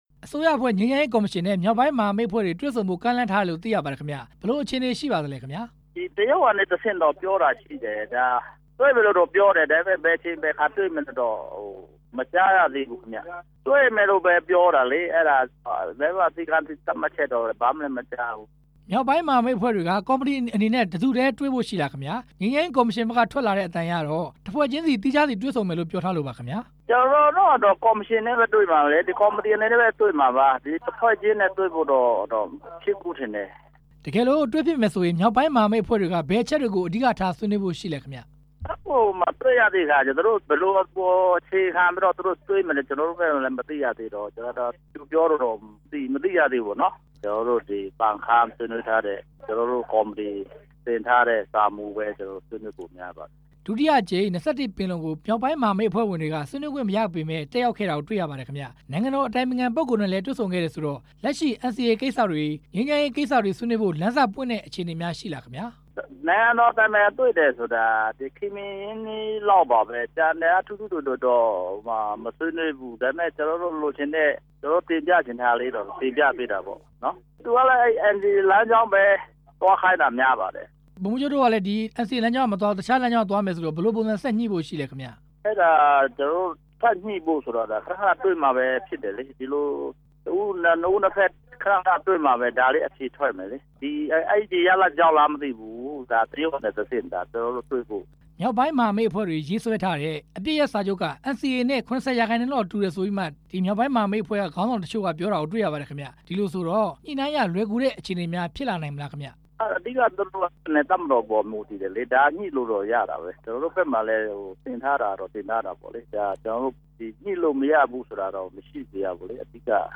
ငြိမ်းချမ်းရေးကော်မရှင်နဲ့ တွေ့ဆုံမယ့်ကိစ္စ TNLA ဒုဥက္ကဌ ဗိုလ်မှူးချုပ် တာဂျုတ်ဂျားနဲ့ မေးမြန်းချက်